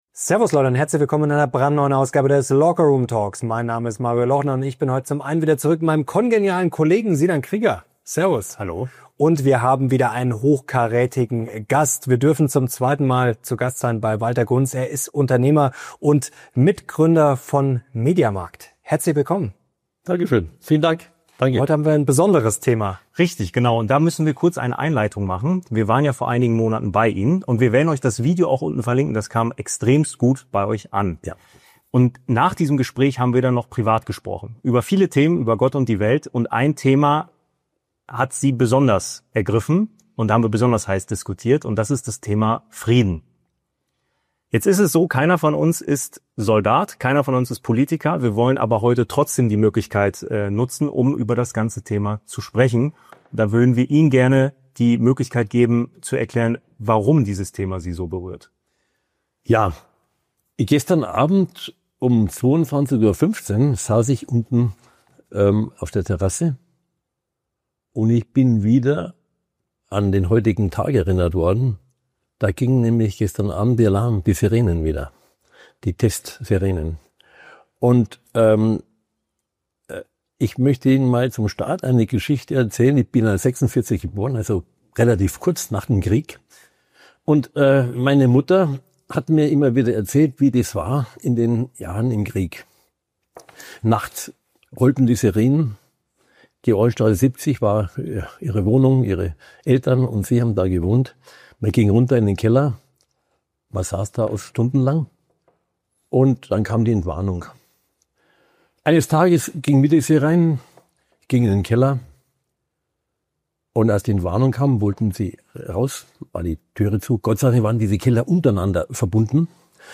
In diesem besonderen LoKr Room Talk erklärt die Unternehmerlegende, warum wir vor einem möglichen 3. Weltkrieg stehen, welche Verantwortung jeder Einzelne trägt – und wie wir wieder lernen können, zu vergeben. Ein Gespräch über echte Toleranz, Führung und die Kunst der Diplomatie.